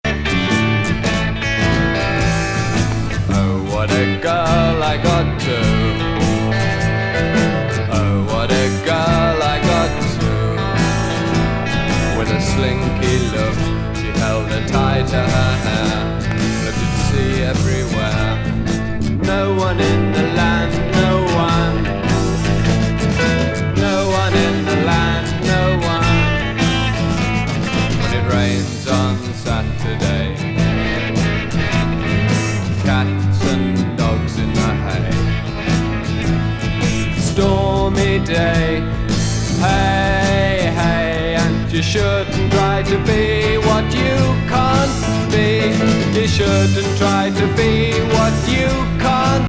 クレイジー・ダイアモンド節全開のアシッド・フォーキー